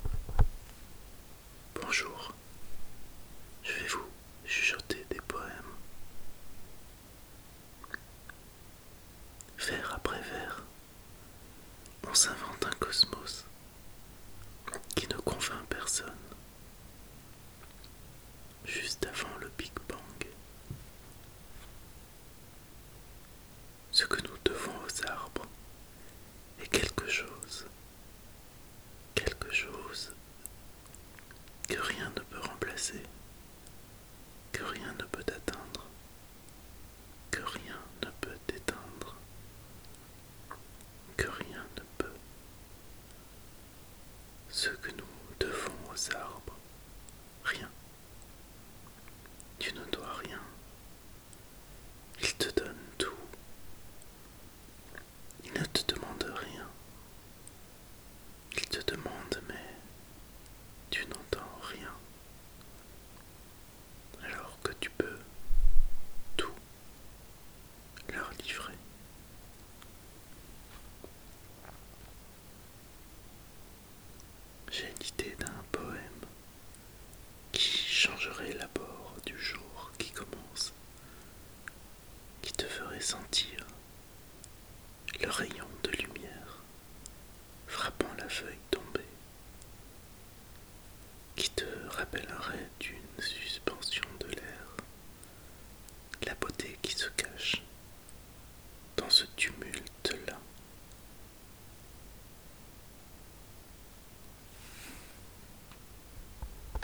Poésie ASMR #1
dans Poésie sonore
Des audios ou des vidéos chuchotés qui, par des mots, des bruits, permettraient la relaxation et le déclenchement d’un stimuli semblable à un frisson à l’arrière du crane et le long de l’échine.